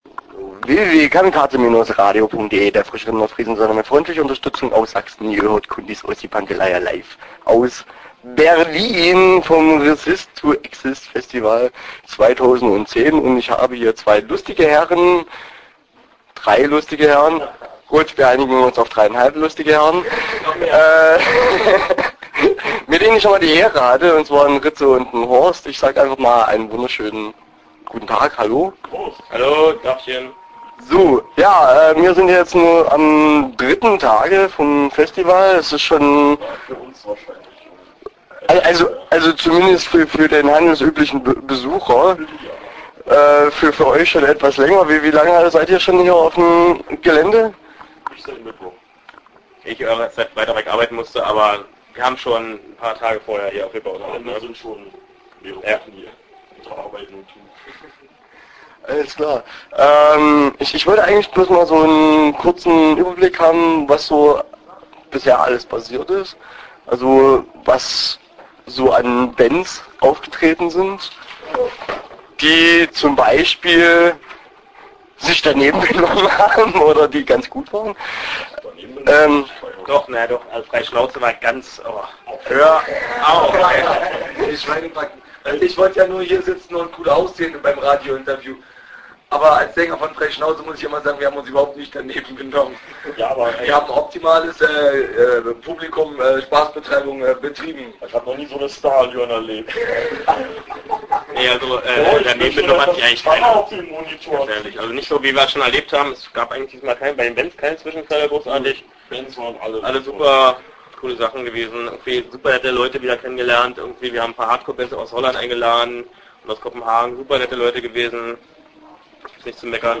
Interview Teil 1 (18:55)